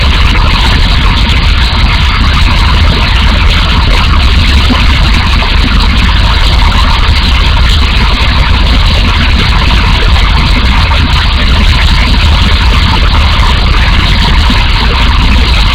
waterfall.ogg